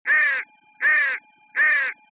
Crow Kraa Sound Effect Free Download
Crow Kraa